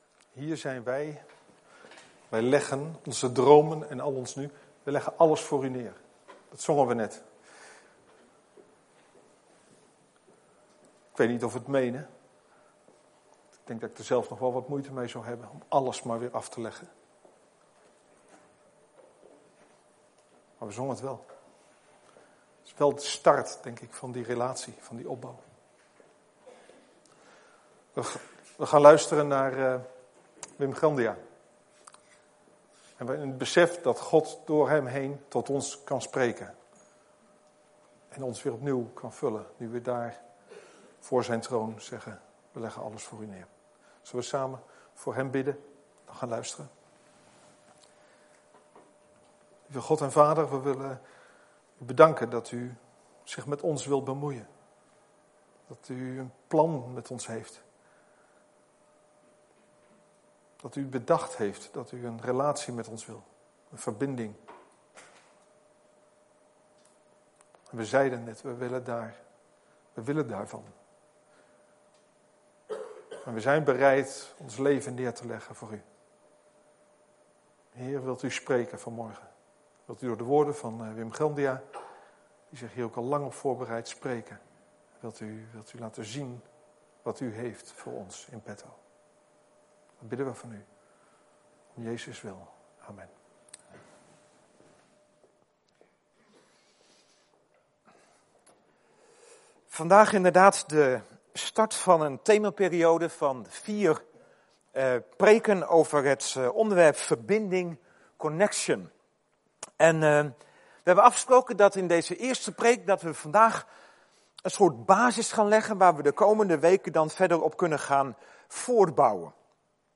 We komen elke zondagmorgen bij elkaar om God te aanbidden.